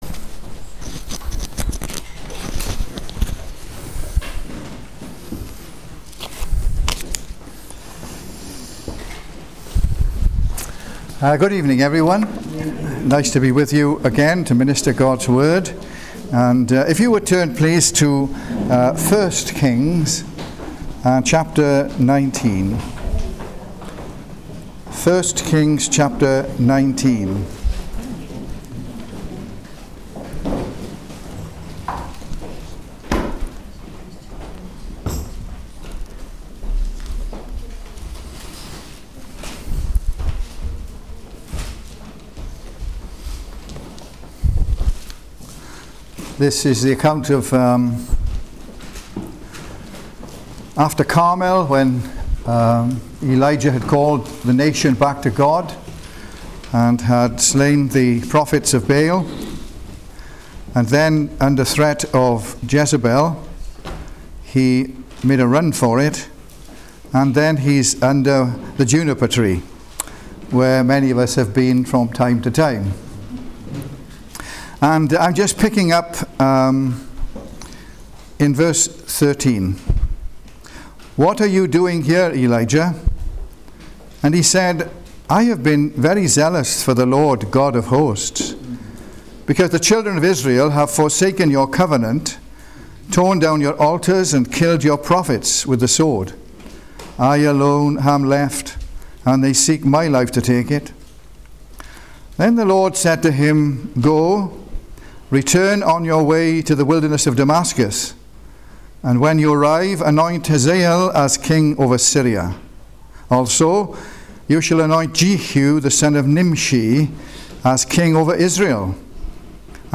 1 Kings 19:13-21 Service Type: Christian Alliance Ministries Conference « The Tale of Two Rivers “They Overcame him” »